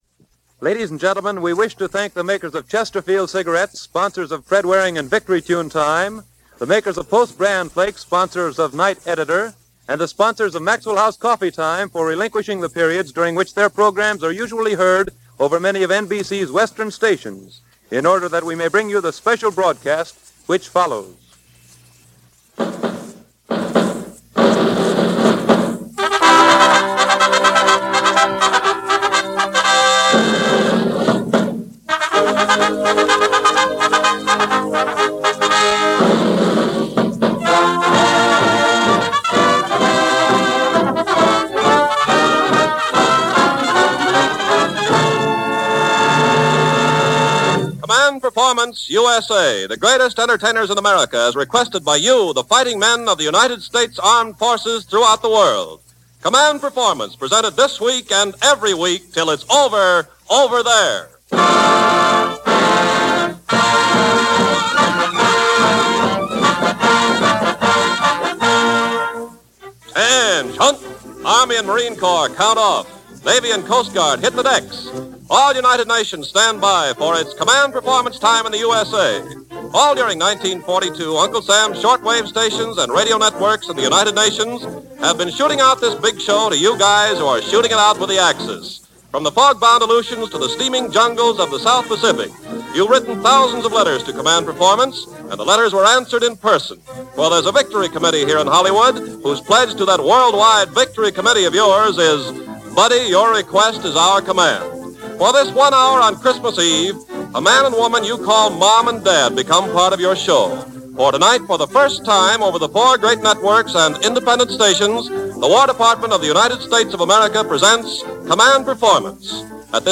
All Radio Networks – Command Performance – Dec. 24, 1942 –